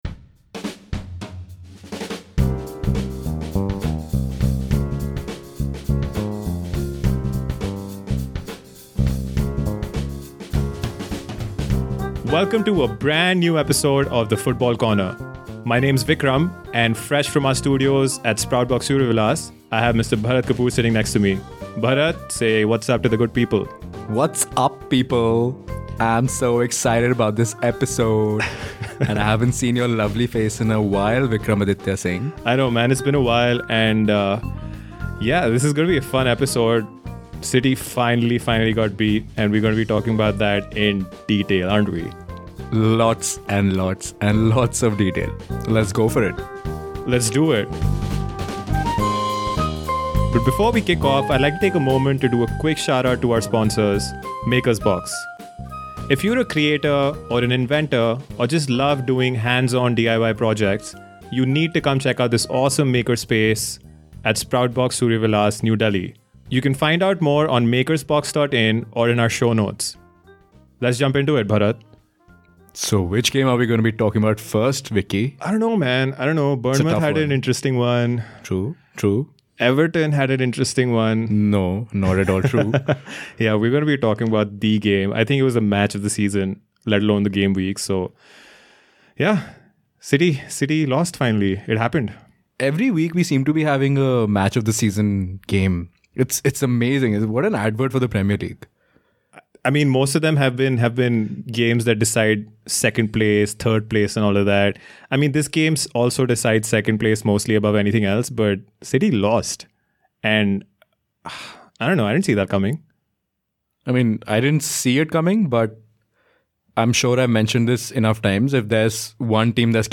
TFC is back with a brand new episode in which the boys discuss their take on City's first loss of the season, Arsene's thoughts behind dropping Sanchez and taking a loss, United's strengthening on 2nd place along with their pursuit of Sanchez and Chelsea's continuing run of games without a win.